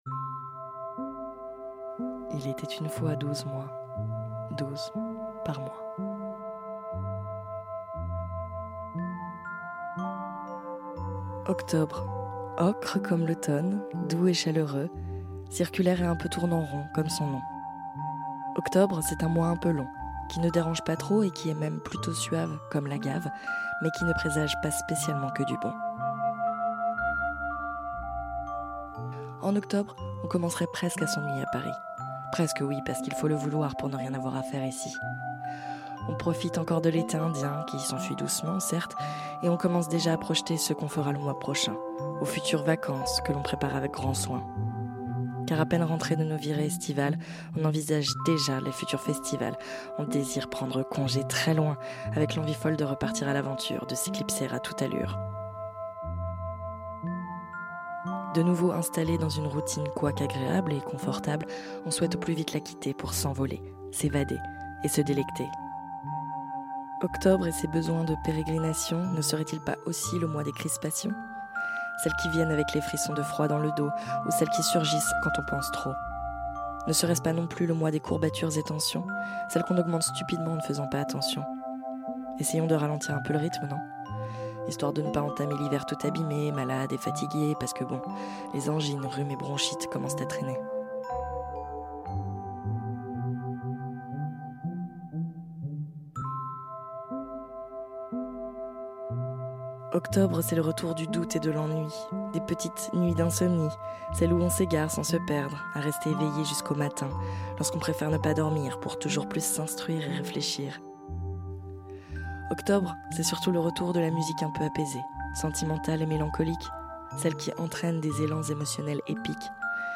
Soundtracks : Delia Derbyshire - Air / Doon Kanda - Axolotl